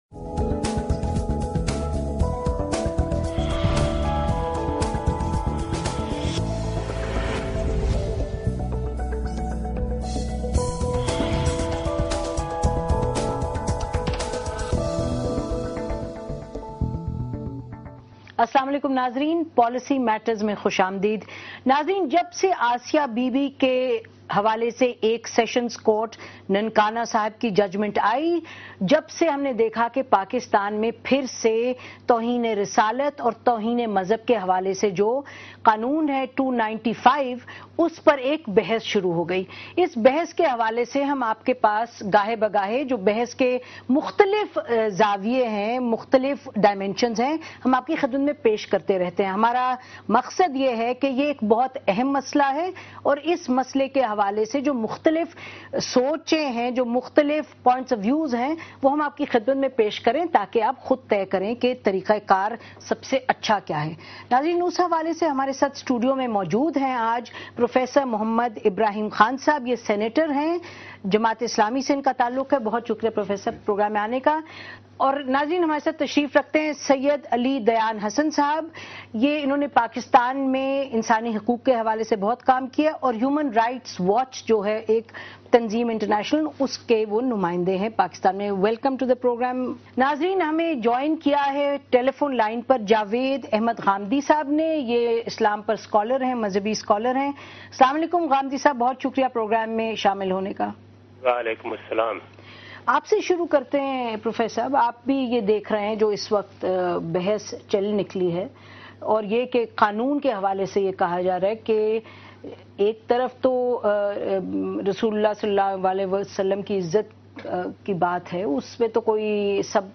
A debate